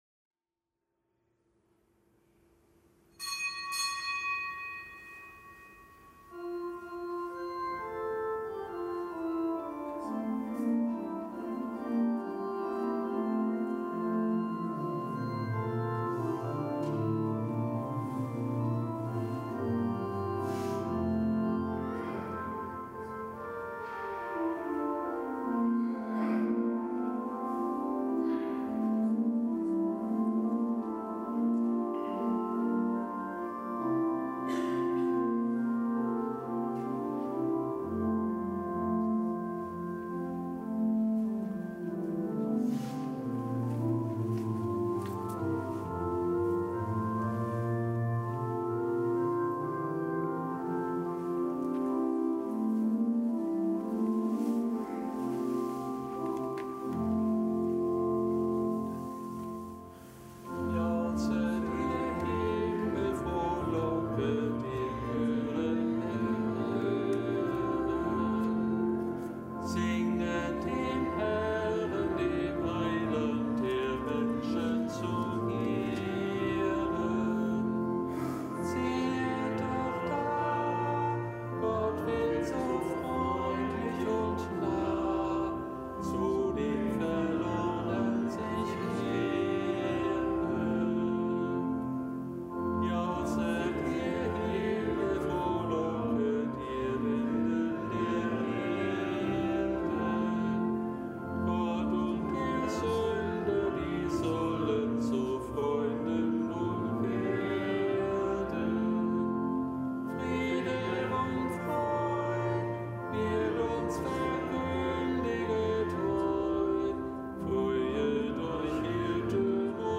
Kapitelsmesse aus dem Kölner Dom am Mittwoch der Weihnachtszeit.
Zelebrant: Weihbischof Rolf Steinhäuser.